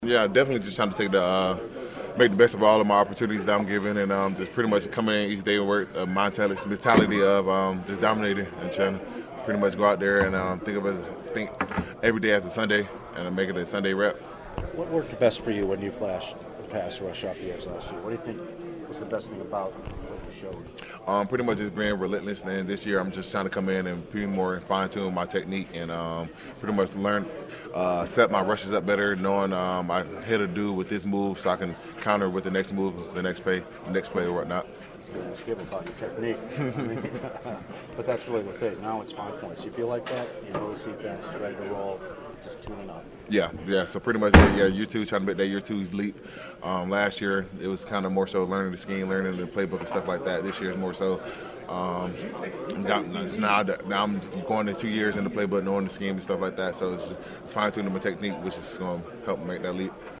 I caught up with Enagbare after practice and he’s well aware the window of opportunity for more playing time has been opened a little wider with the transaction.